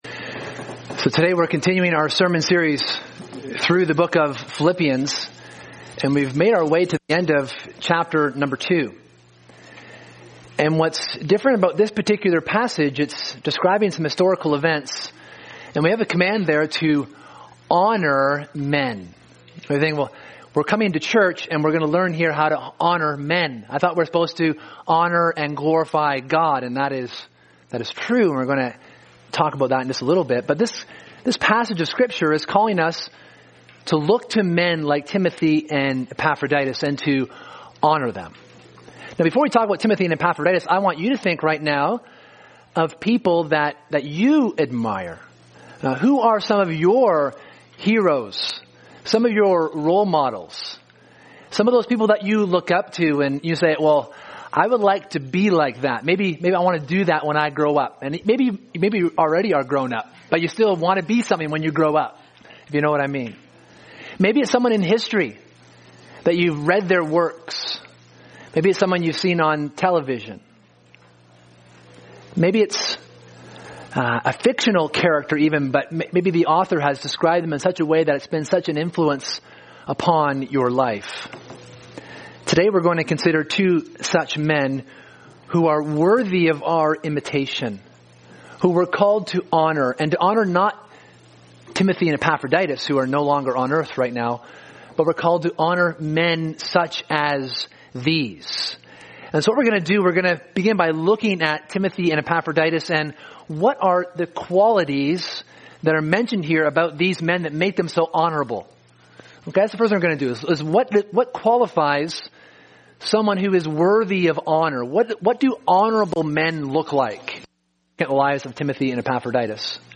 Sermon: Honoring Imitators of Christ